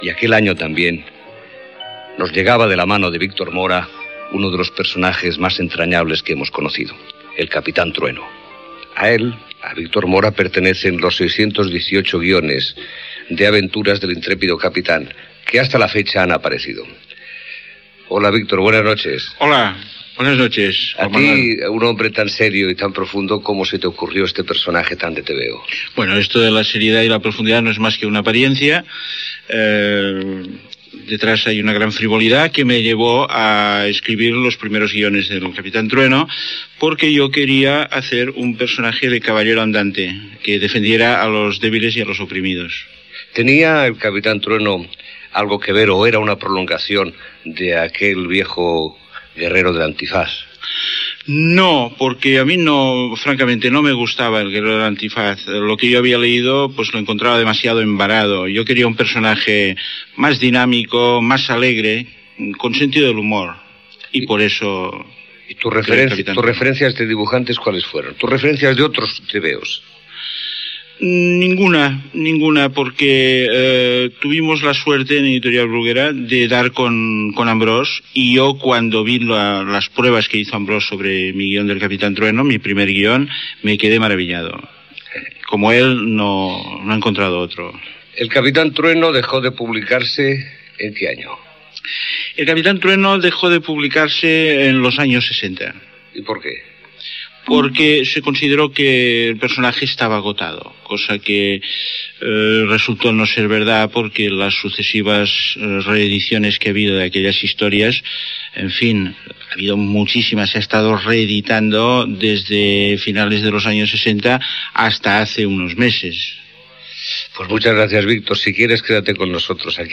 Entrevista a l'escriptor Víctor Mora, creador del còmic "El capitán Trueno" l'any 1956
Divulgació